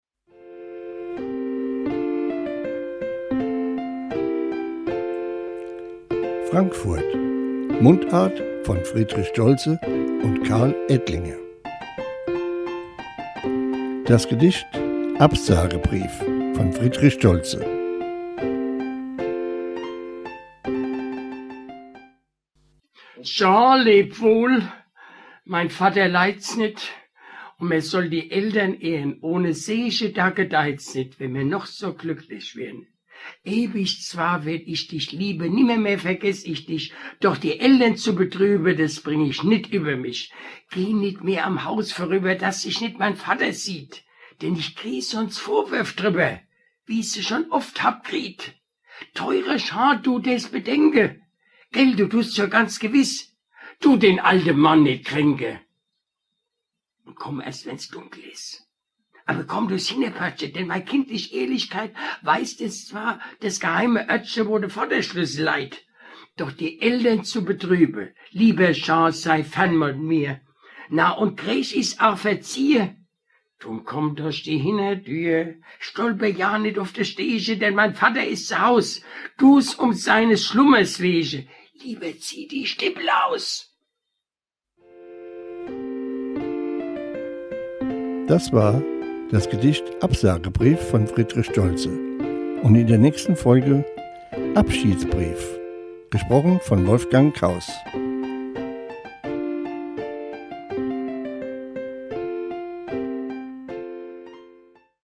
Mundart von Friedrich Stoltze "Absagebrief"
Gedicht: Absagebrief